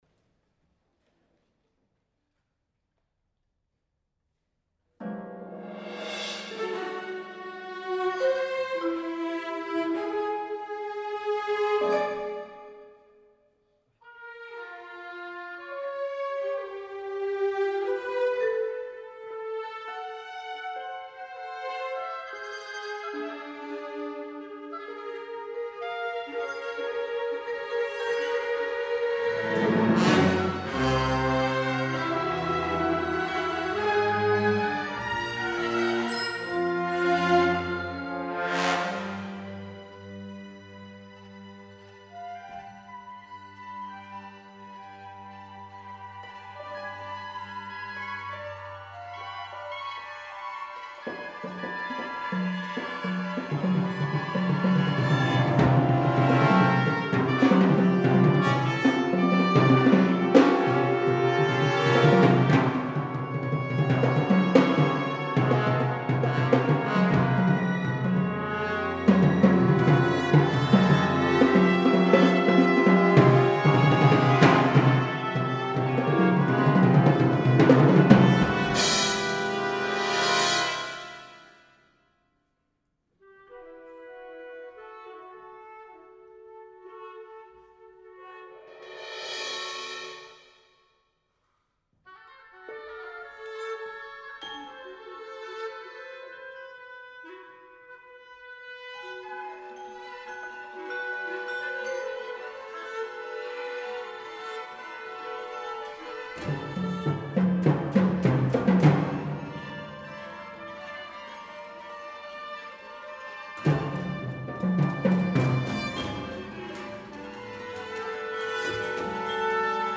Composer of contemporary classical music